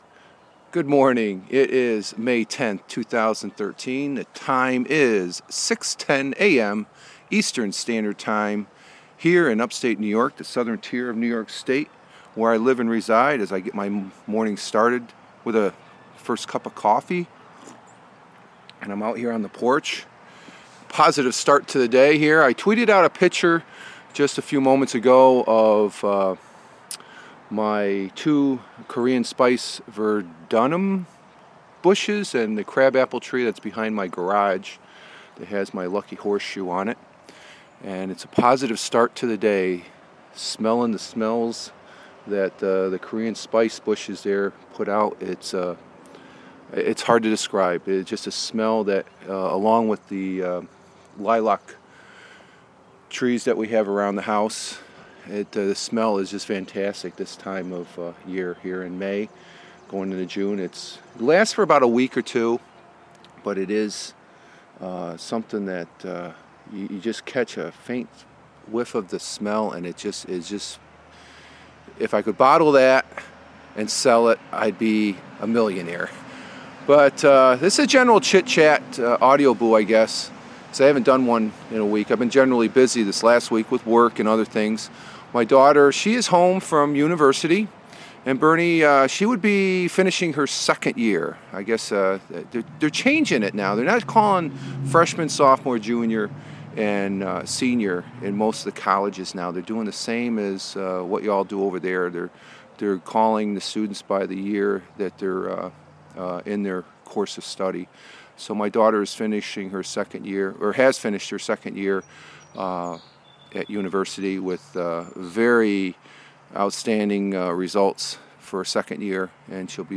Getting my day started out here on the back porch, doing a general chit chat Audioboo....